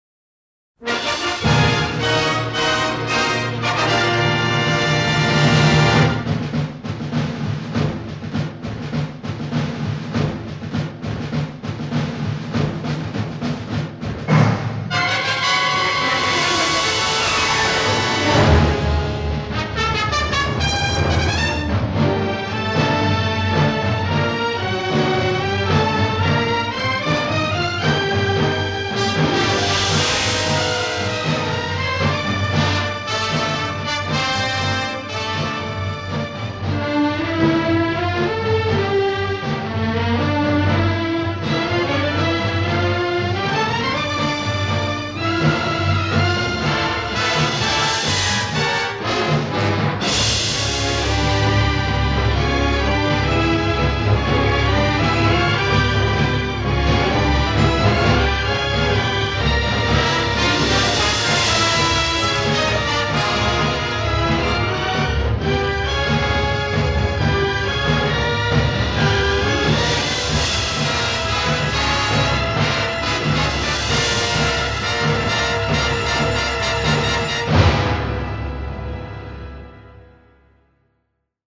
reflète le son de l’époque (ça pourra en rebuter)
sur-expressifs (les cuivres tonitruants)